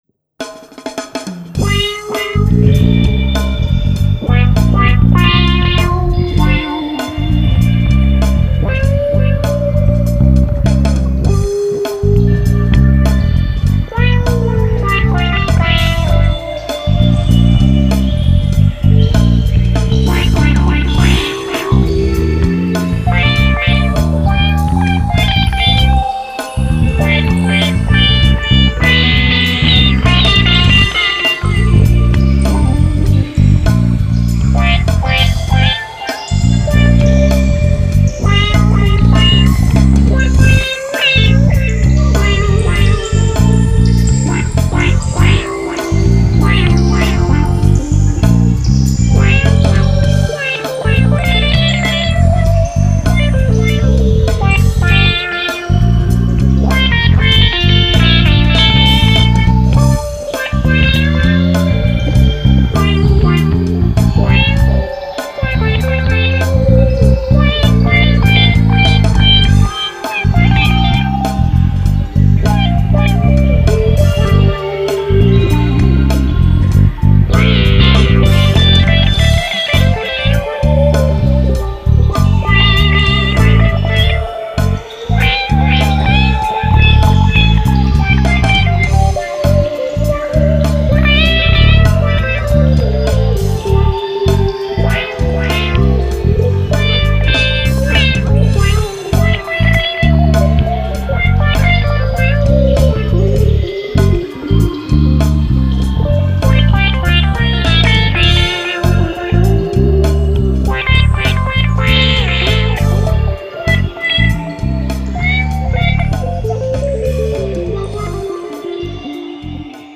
Habe hier am Mac heute mal ein bischen mit den Plugins herumexperimentiert und übriggeblieben ist ein Mix aus viel Delay und etwas Filter, soll heißen, alles was neu dazugekommen ist, ist Gitarre, no Sythesizers have been harmed during the recording of this track.